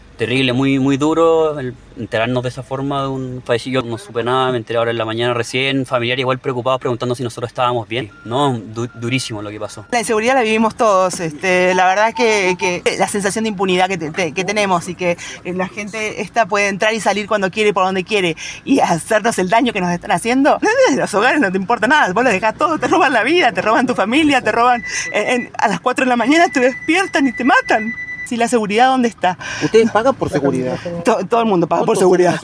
Los vecinos del sector se refirieron al lamentable suceso en diálogo con Radio Bío Bío.